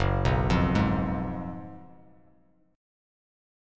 F#M7sus2sus4 Chord
Listen to F#M7sus2sus4 strummed